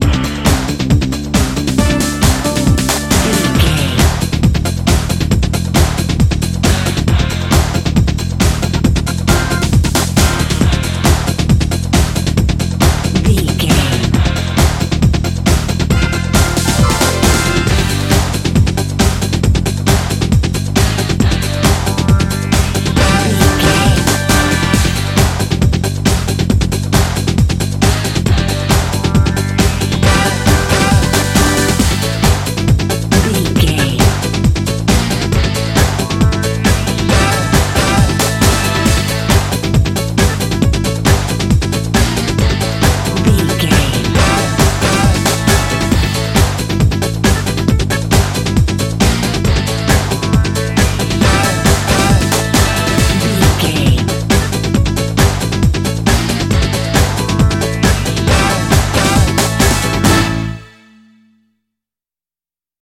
Ionian/Major
D
synthesiser
drum machine
Eurodance